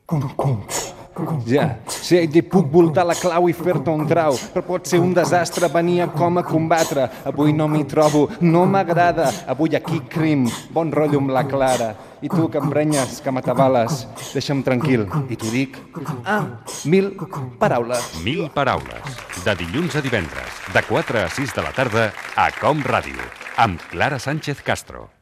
Promoció cantada del programa